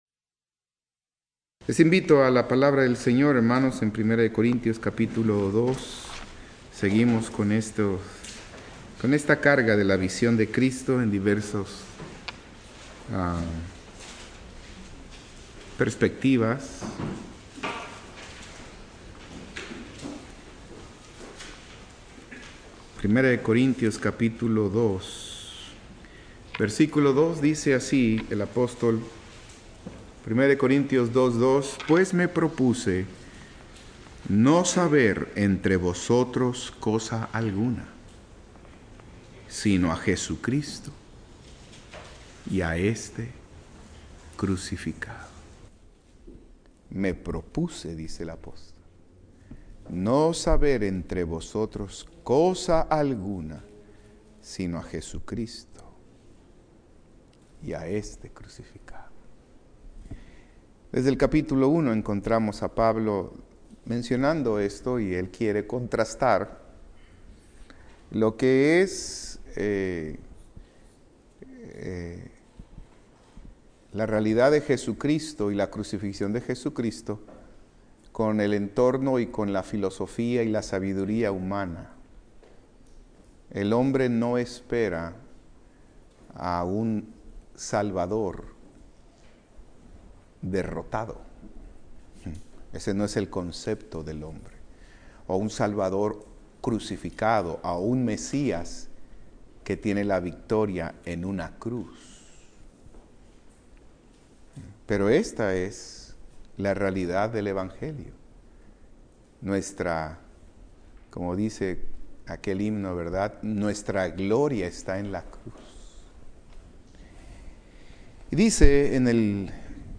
Servicio miércoles